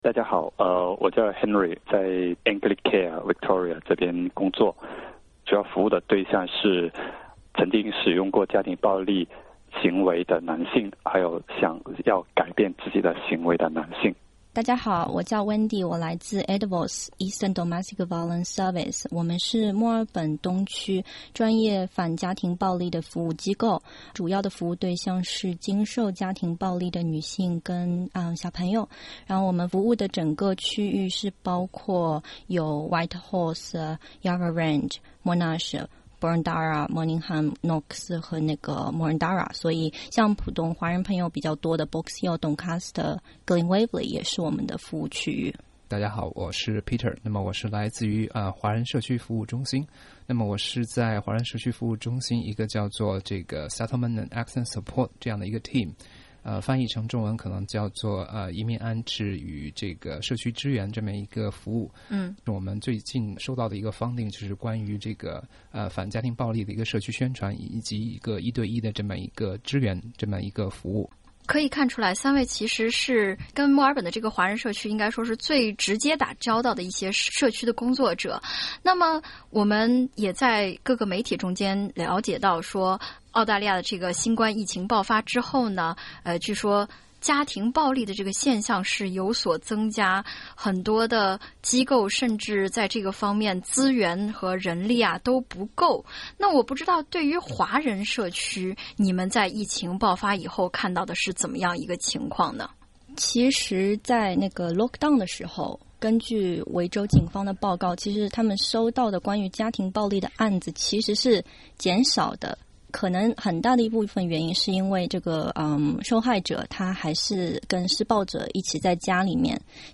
很多澳洲华人遭受到家庭暴力以后，甚至意识不到自己受到了家庭暴力的对待。维州的三位社区工作者说，在澳大利亚家庭暴力的界定比较广泛，只要对自己在家中的安全感到担忧，就应该敲响警钟（点击封面图片收听详细采访）。